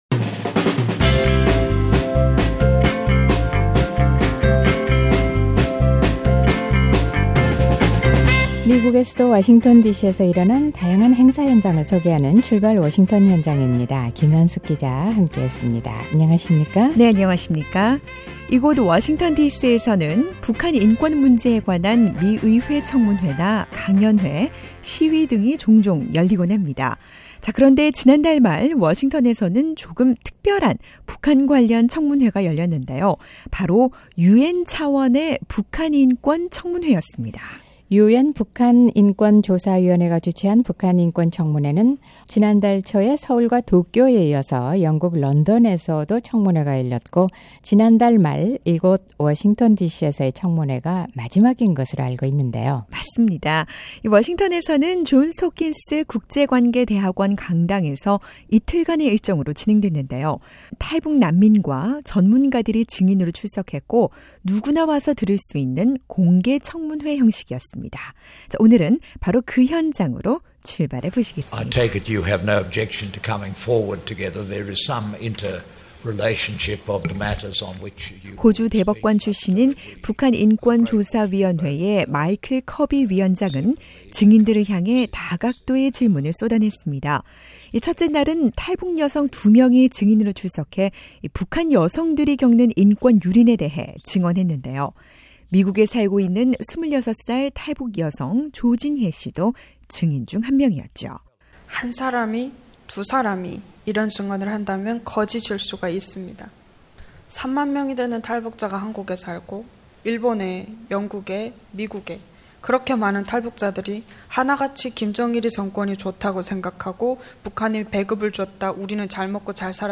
이틀간 진행된 청문회에서 증인으로 출석한 탈북자들과 북한 전문가들은 과연 어떤 증언을 했을까요? 북한 인권의 현실에 대한 충격과 아픔뿐 아니라 북한 인권에 대한 기대와 희망도 갖게 했던 청문회 현장으로 출발해봅니다.